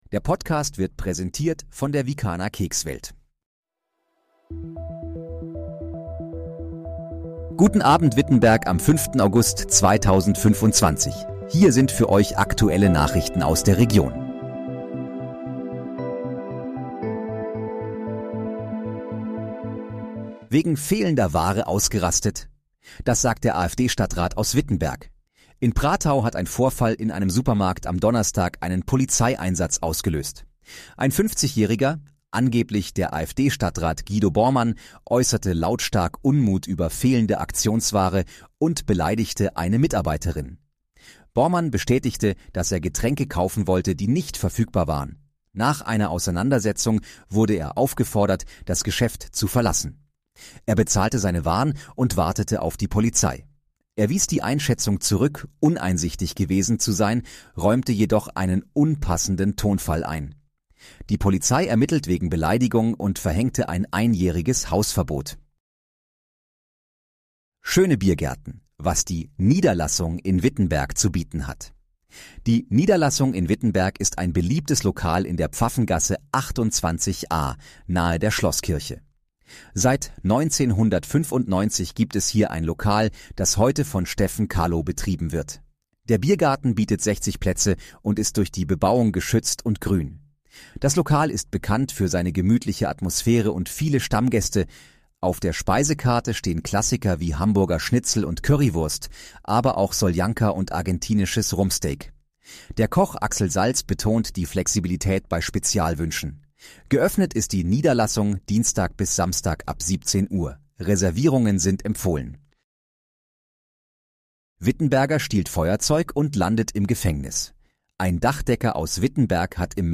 Guten Abend, Wittenberg: Aktuelle Nachrichten vom 05.08.2025, erstellt mit KI-Unterstützung
Nachrichten